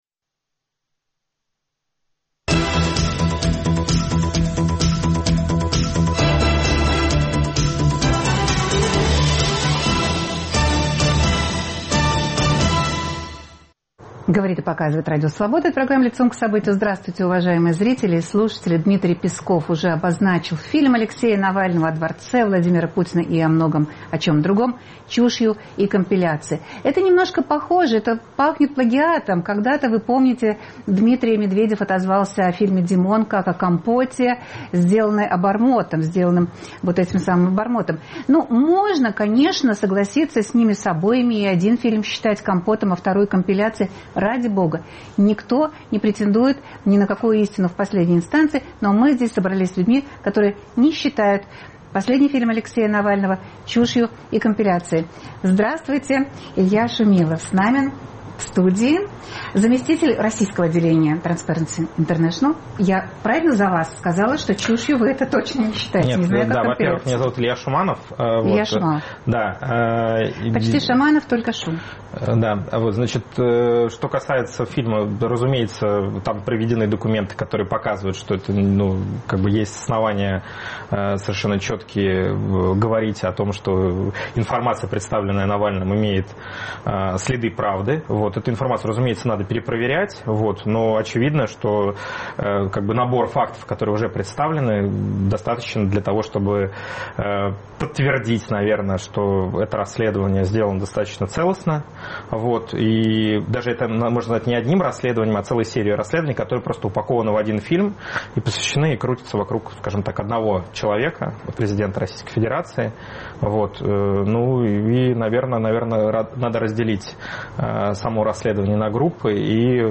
Но что будет с тем же дворцом в случае вполне естественного физического конца человека, для которого его возводили? Разбираемся с гостями программы.